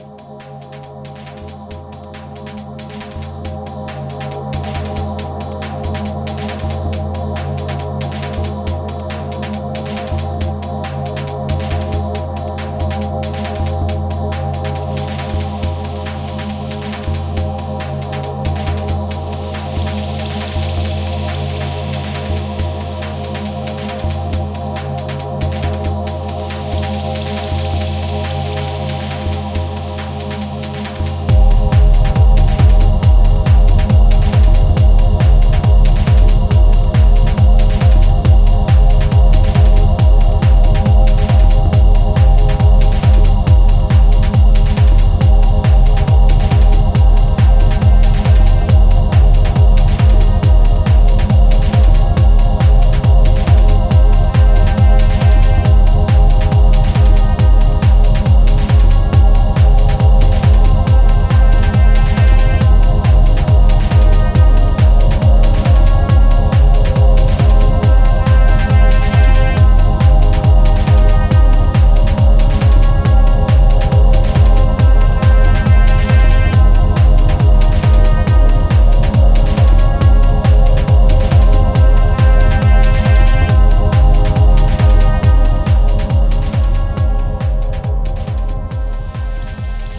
圧の強いサブベースを伴って疾走するキックの迫力がピークタイムにふさわしい